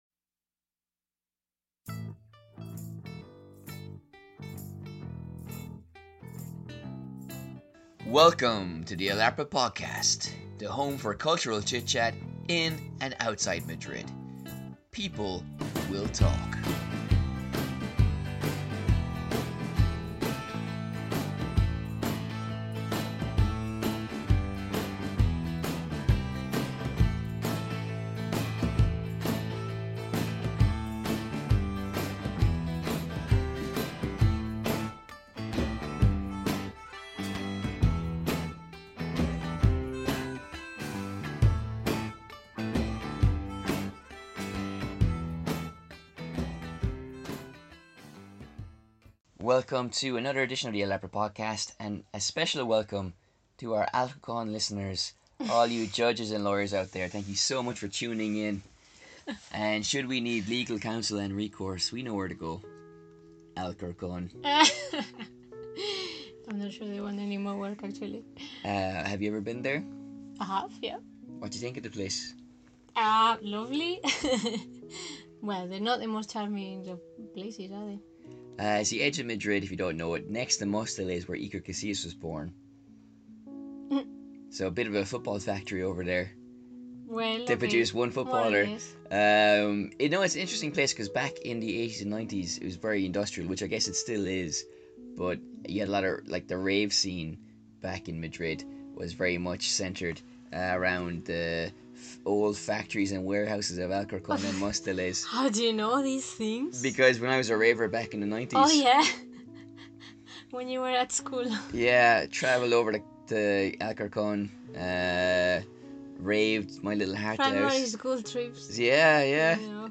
'The Last Girl' Book Review